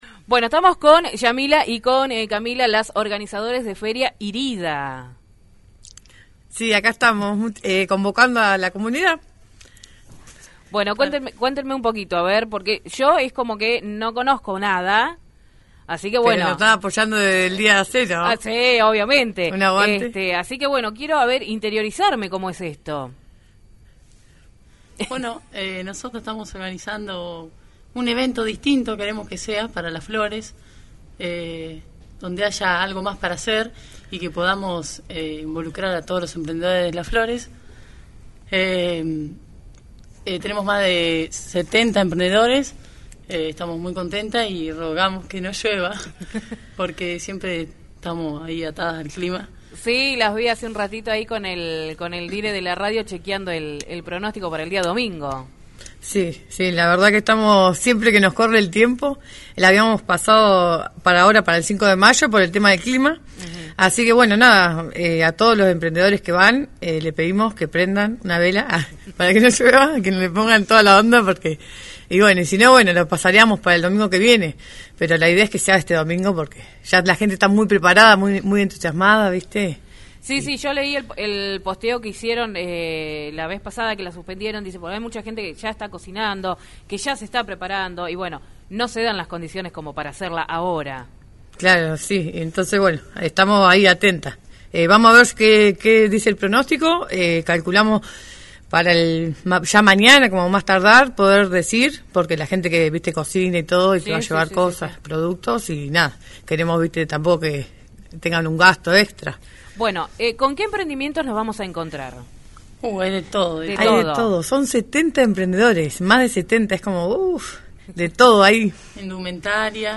visitaron el programa «Súbeme la radio»